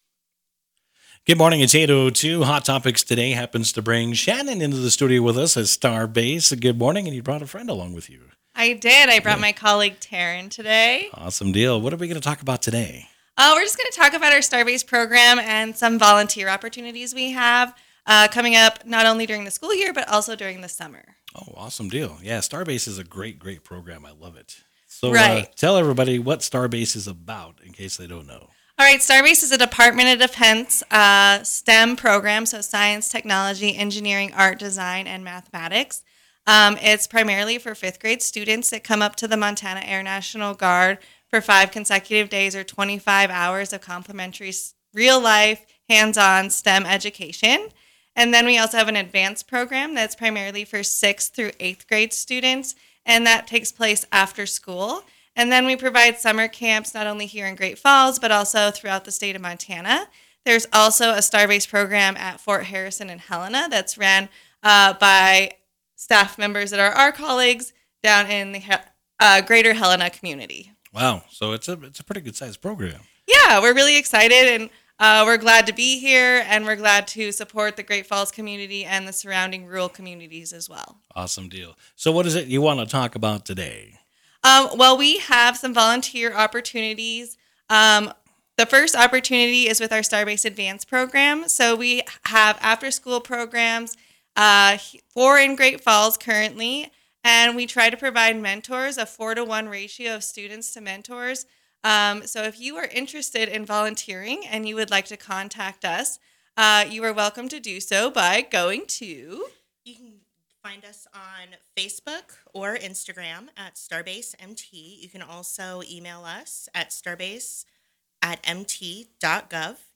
STARBASE Great Falls Radio Recordings STARadio